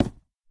Minecraft Version Minecraft Version snapshot Latest Release | Latest Snapshot snapshot / assets / minecraft / sounds / block / dried_ghast / wood3.ogg Compare With Compare With Latest Release | Latest Snapshot
wood3.ogg